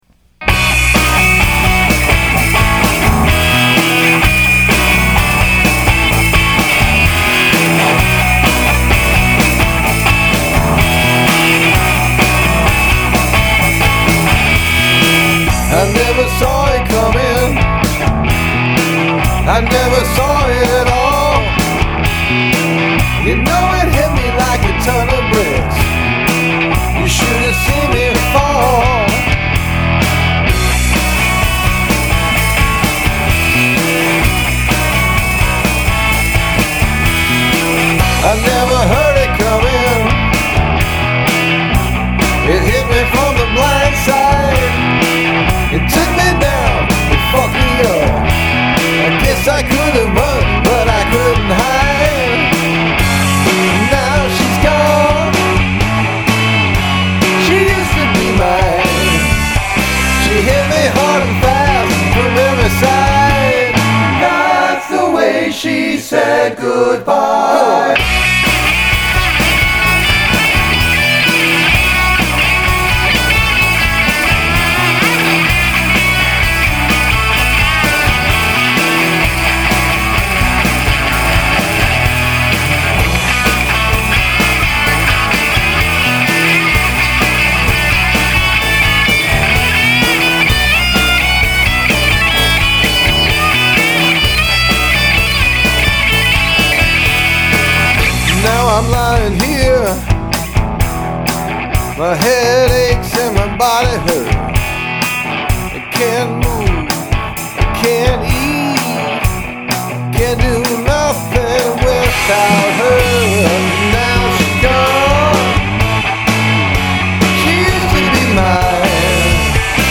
Use of choral voices
Turn up the solo!